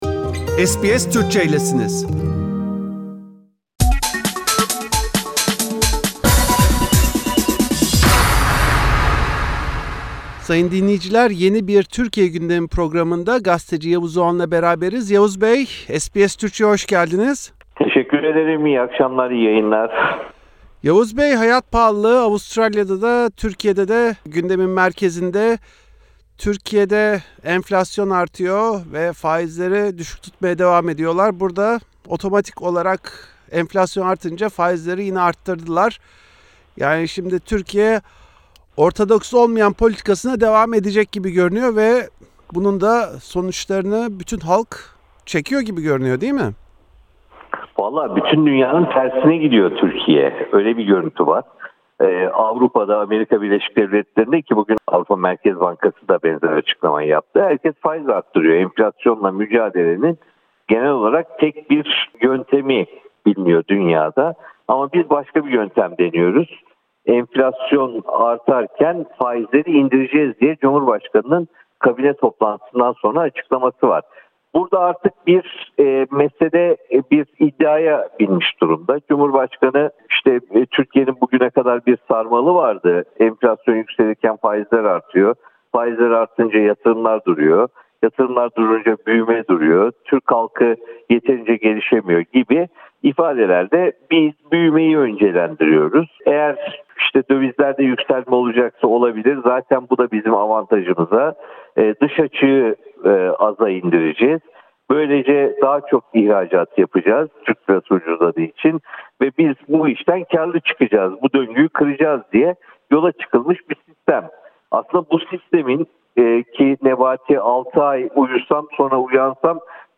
Gazeteci Yavuz Oğhan Türkiye’deki son bir haftayı SBS Türkçe’ye değerlendirdi.